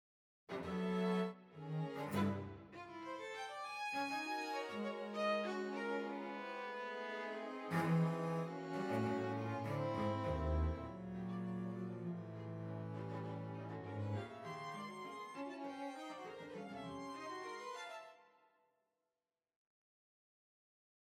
same with NPPE CineStrings Solo: